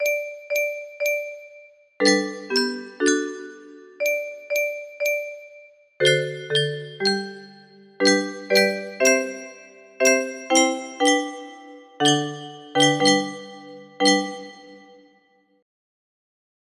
It Is Well With My Soul - Chorus music box melody